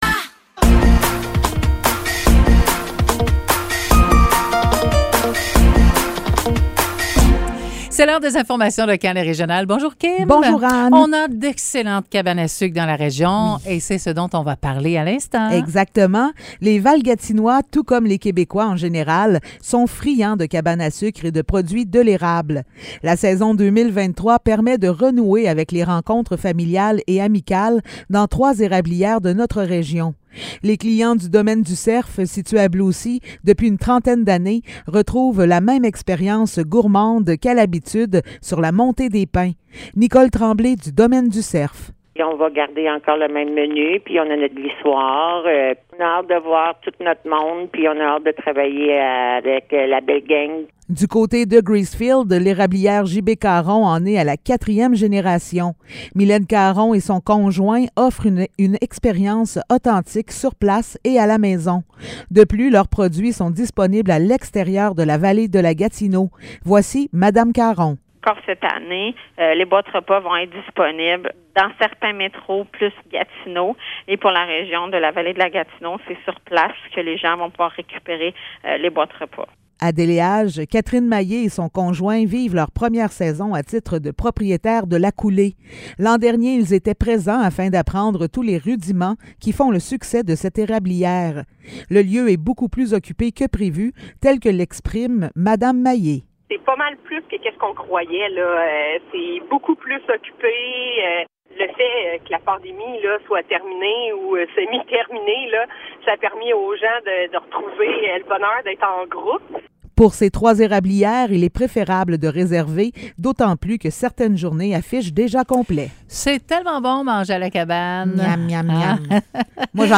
Nouvelles locales - 16 mars 2023 - 10 h